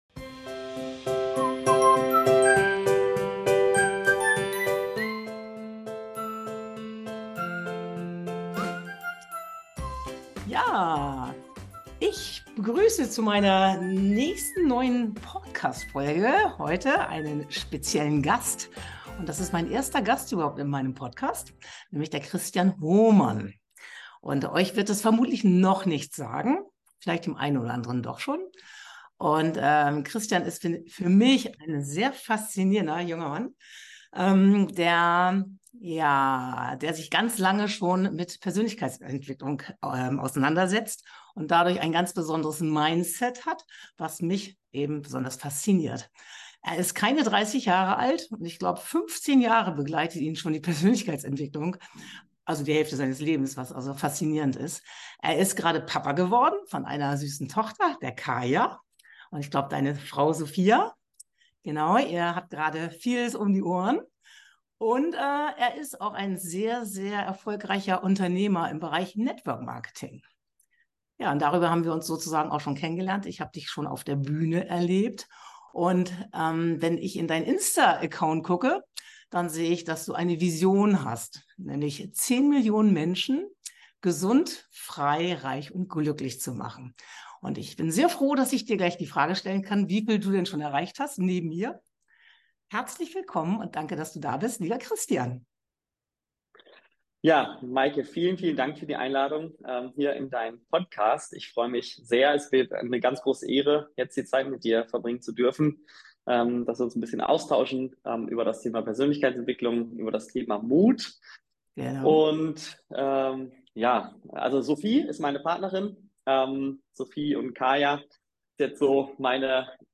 Ein Mutmach-Interview